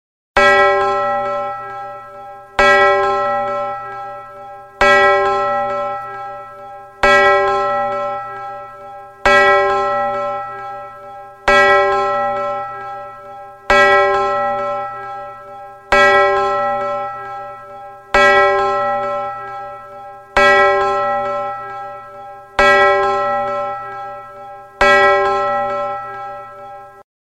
SFX新年钟声音效下载